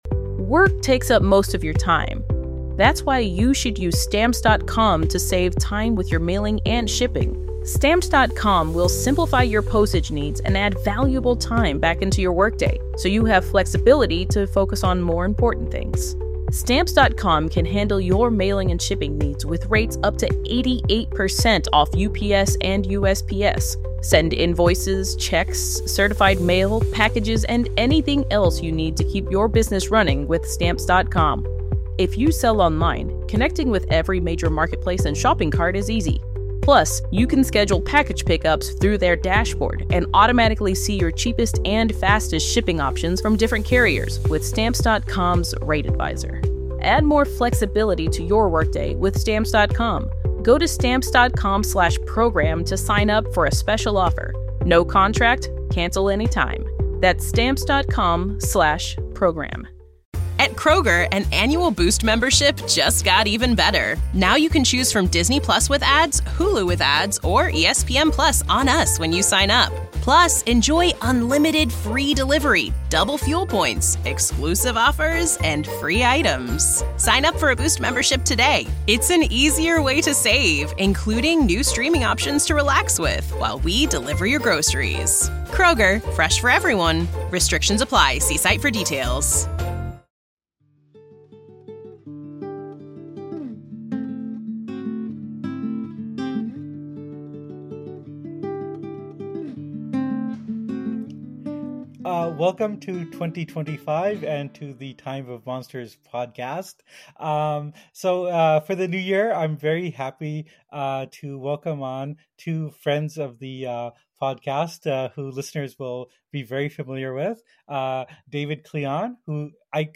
… continue reading 129 epizódok # Society # Politics # News Talk # News # The Nation Company LLC